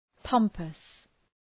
Προφορά
{‘pɒmpəs}